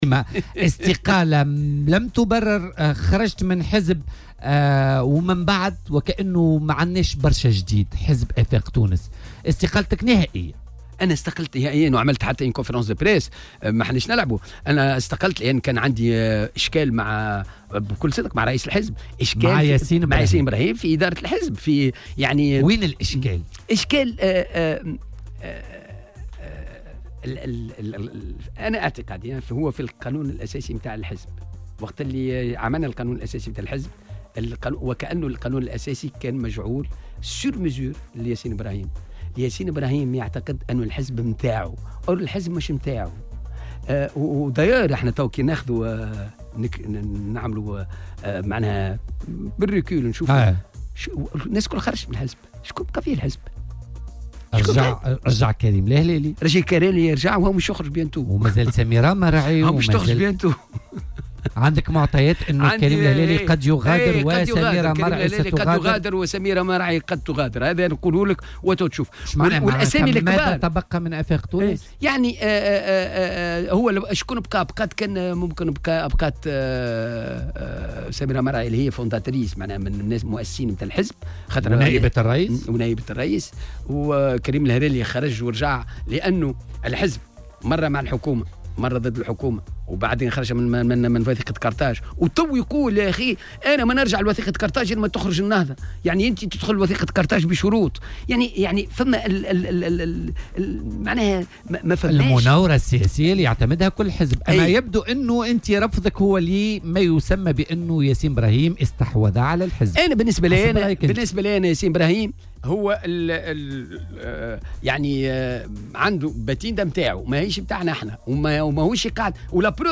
وقال ضيف "بوليتيكا" على "الجوهرة اف ام" ان استقالته من الحزب نهائية بسبب خلافات مع رئيس الحزب ياسين ابراهيم الذي يعتقد أن الحزب على ملكه وينفرد برأيه في الحزب، بحسب تعبيره.